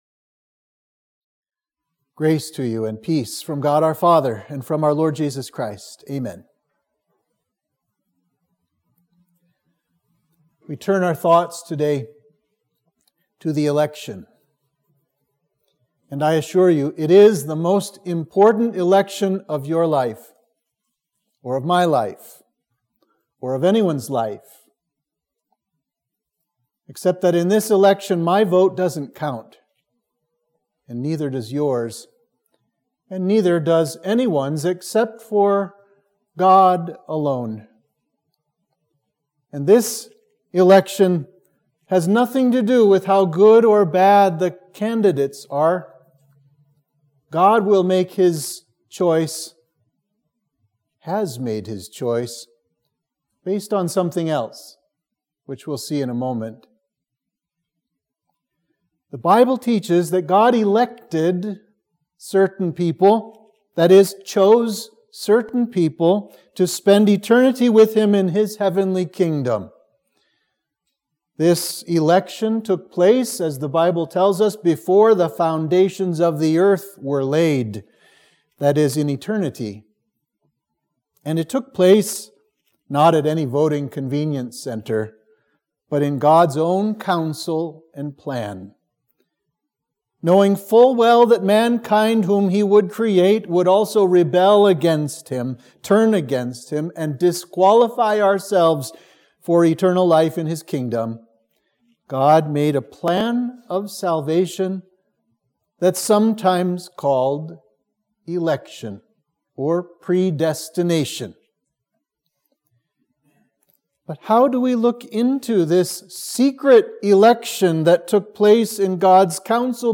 Sermon for Trinity 20